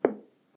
bounce1.ogg